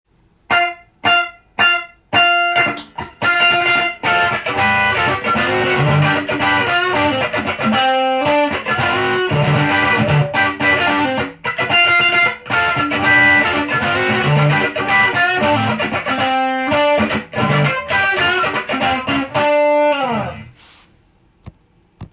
クリアな音なんですが、上品なオーバードライブサウンドが魅力。
音に雑さが全くありません。さすが国産！